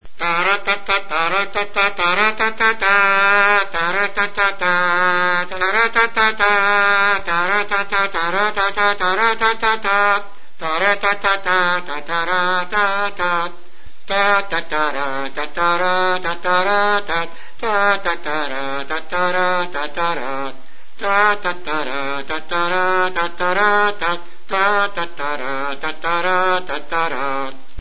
TURUTA-ORRAZIA | Soinuenea Herri Musikaren Txokoa
Membranophones -> Mirliton
Orrazi kontra papera tinkaturik eta beste turutekin bezala kantu-marmarrean doinua ematen da.